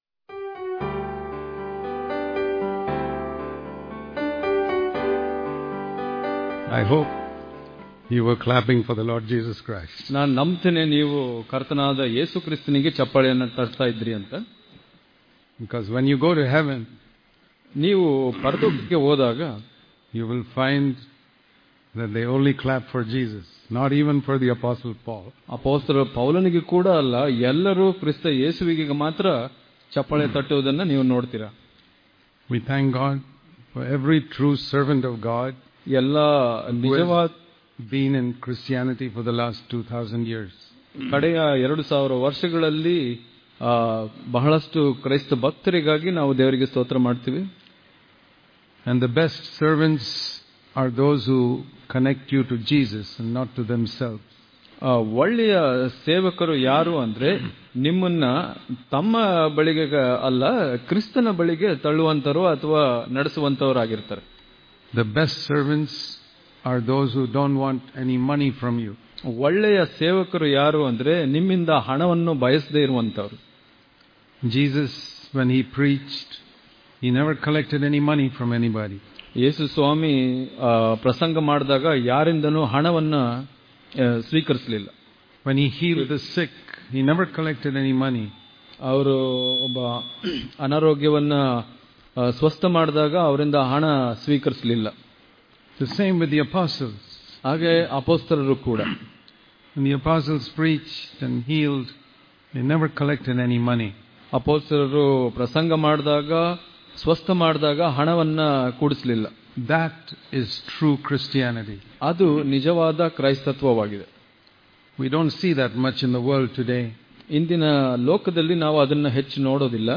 November 25 | Kannada Daily Devotion | We Should Serve Only God Daily Devotions